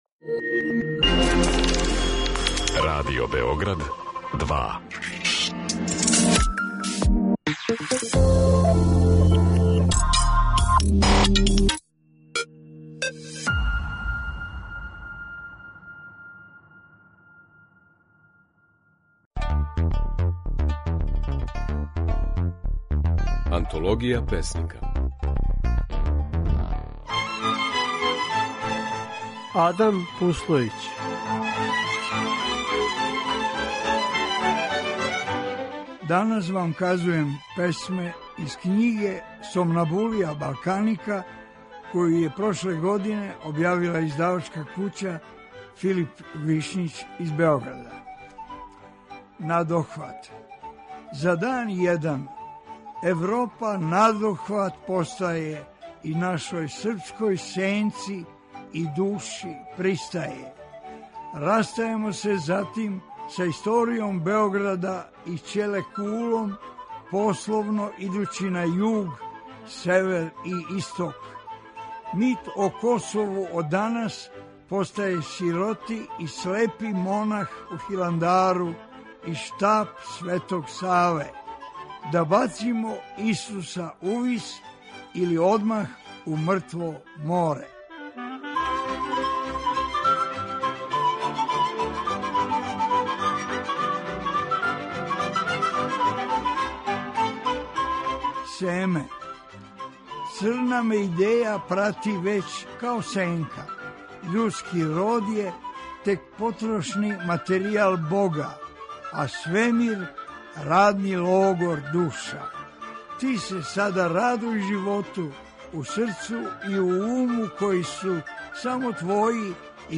У знак сећања на Адама Пуслојића, који нас је напустио последњег дана године за нама, емутујемо стихове у интерпретацији аутора